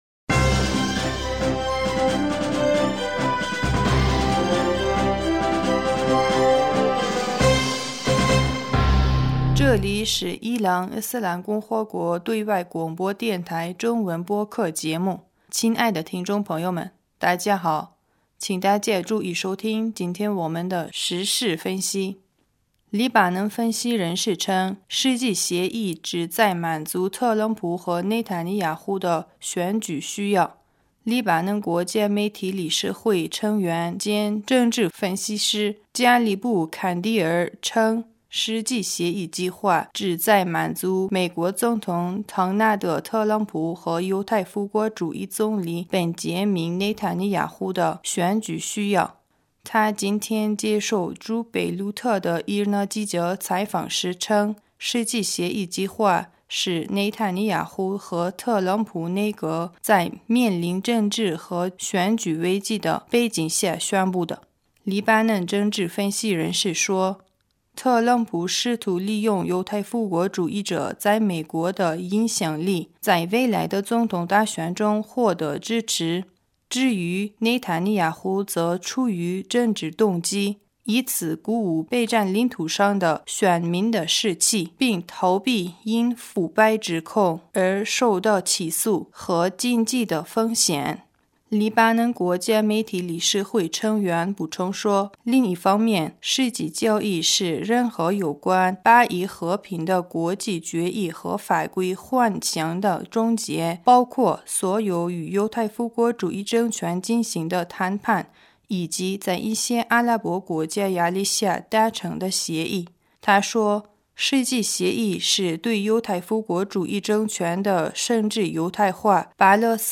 2020 年 2月3 日 新闻